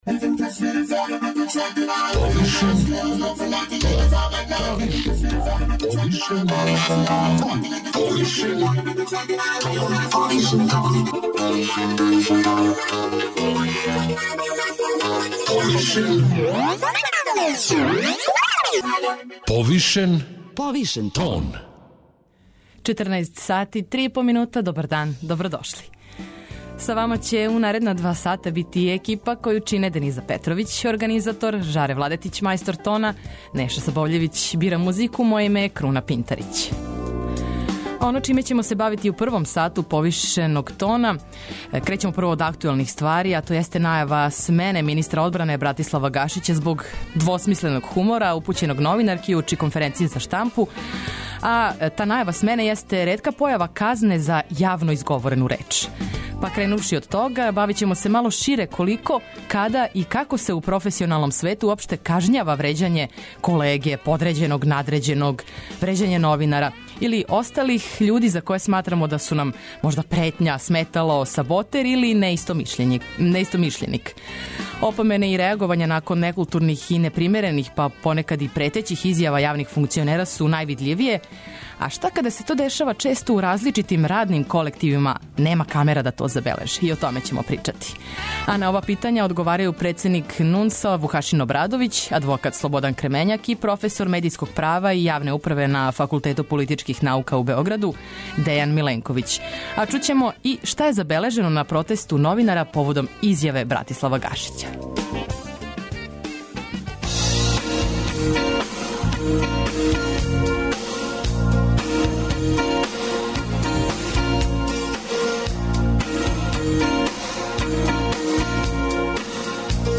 Чућемо и шта је забележено на протесту новинара поводом изјаве Братислава Гашића .